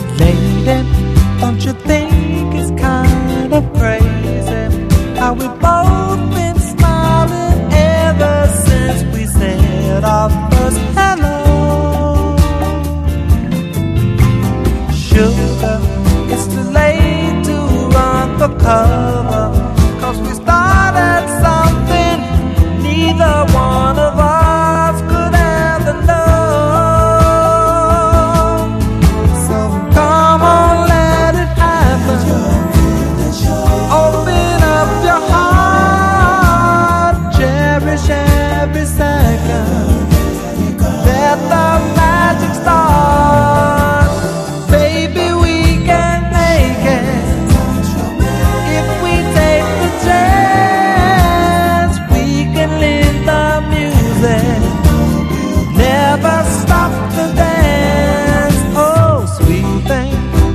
INDIE SOUL / MODERN SOUL (UK)
レア且つ上質なインディー・ソウル/モダン・ファンク連発！